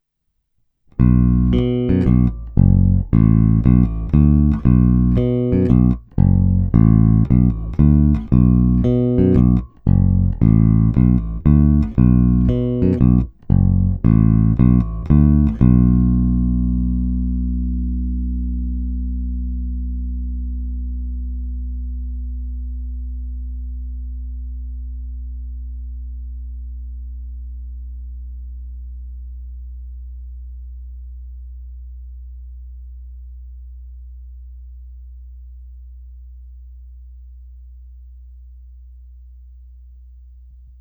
Zvuk je variabilní, plný, čitelný, má ty správné středy, díky kterým se prosadí v kapelním zvuk a taky jej pěkně tmelí.
Snímač u krku